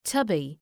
Shkrimi fonetik {‘tʌbı}